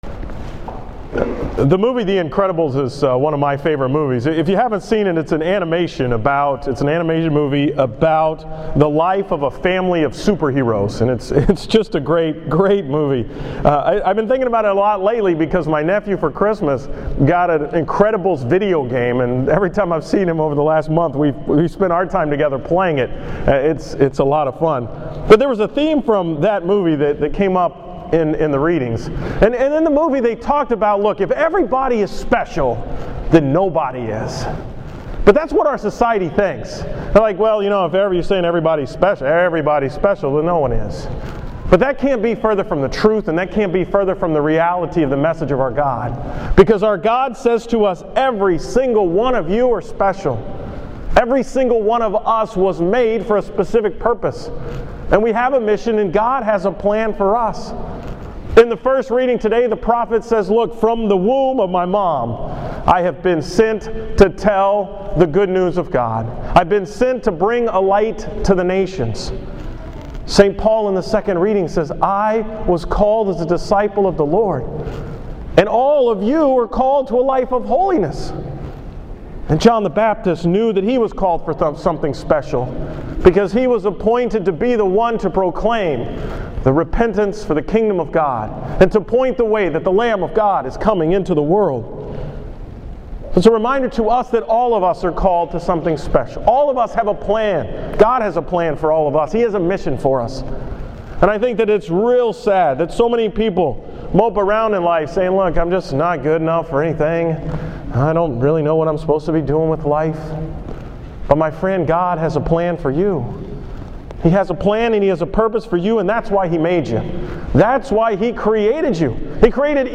From the 11 am Mass on Sunday, January 19, 2014
Category: 2014 Homilies